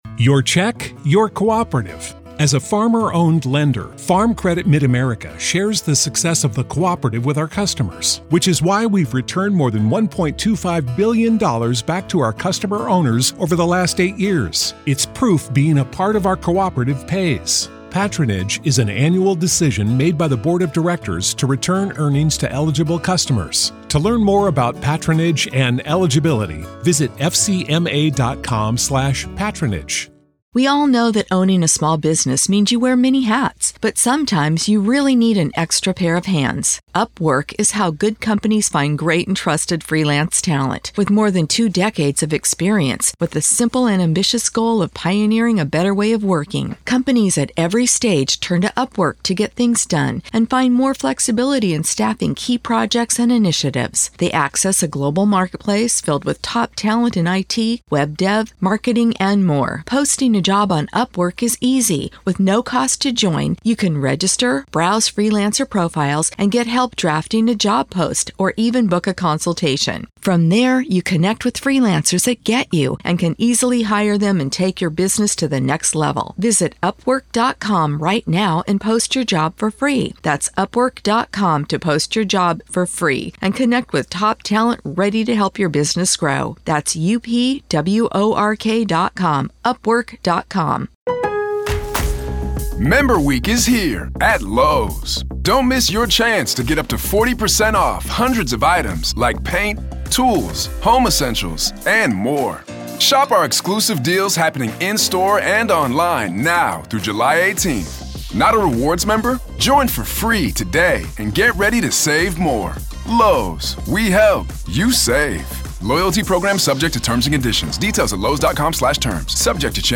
During a hearing on Thursday, both the defense and prosecuting attorneys agreed to the proposed trial schedule. Latah County District Judge John Judge detailed the timeline, noting that it includes two weeks for jury selection, eight weeks for the trial itself, and an additional two weeks for the verdict, sentencing, and any other remaining matters.